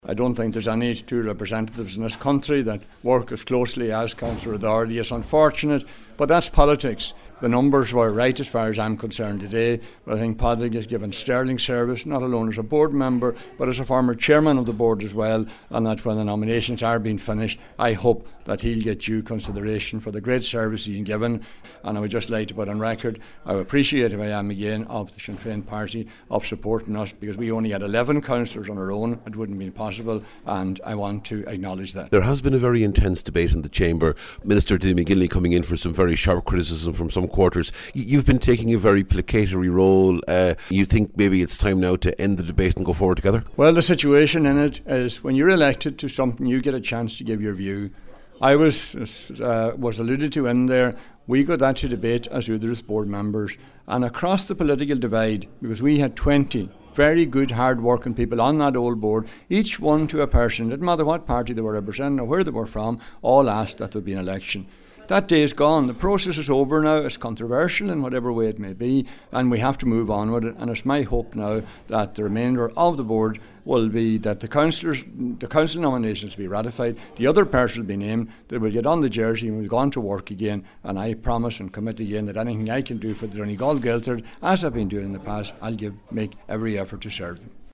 Accepting the nomination, Cllr Alcorn paid tribute to Cllr Padraig O’Dochartaigh, and also to Grainne Mc Geidigh of Sinn Fein and Senator Brian O’Domhnaill of Fianna Fail, the other outgoing Donegal representatives.